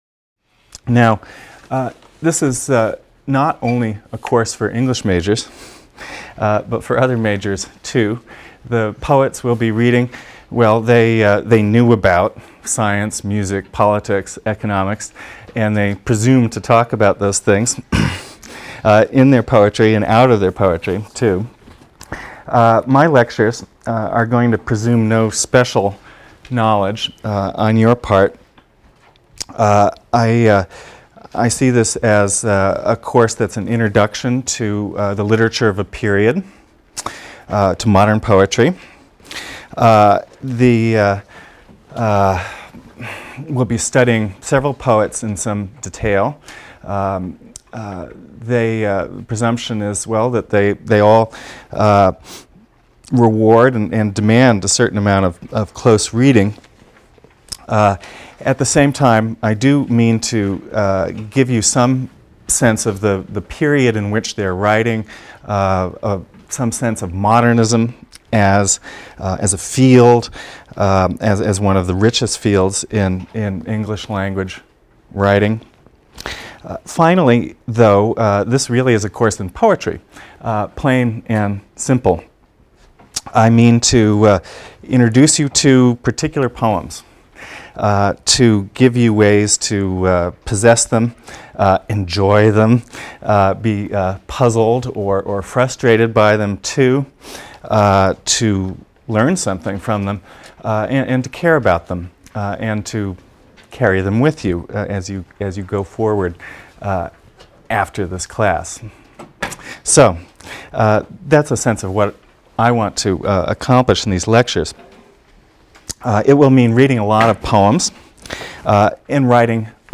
ENGL 310 - Lecture 1 - Introduction | Open Yale Courses